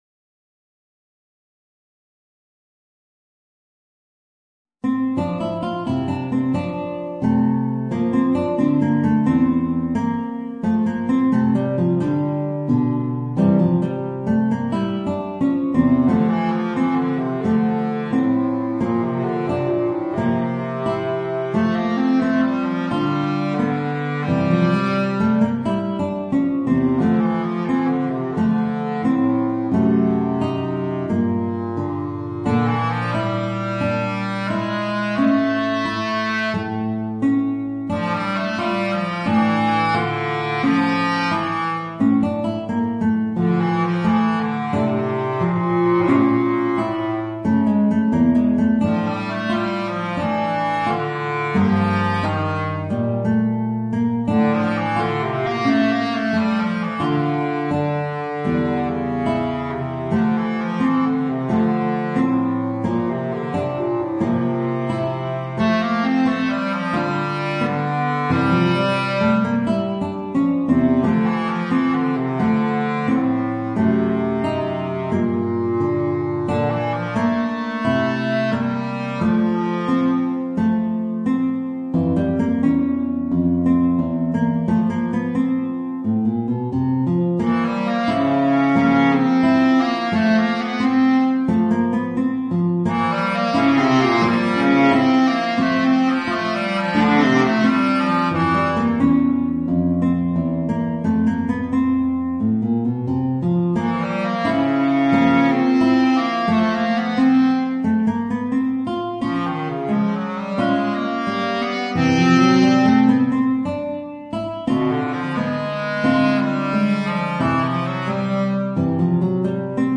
Voicing: Guitar and Bass Clarinet